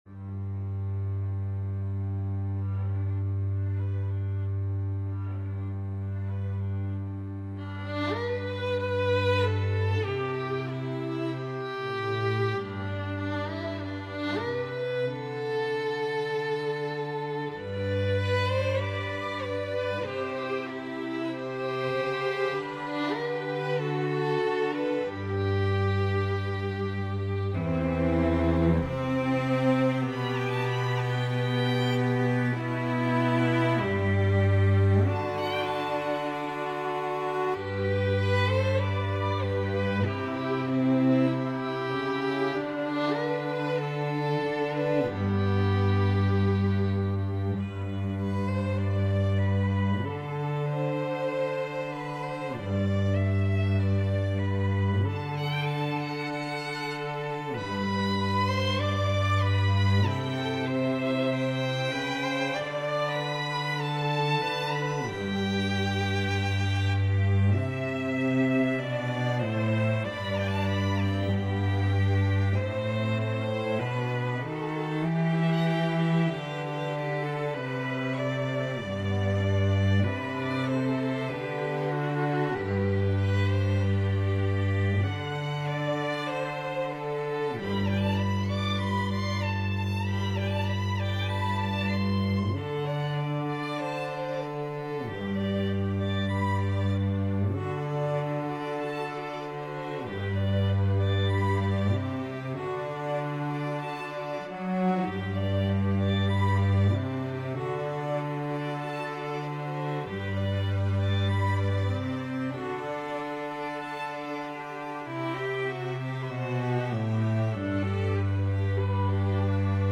Medley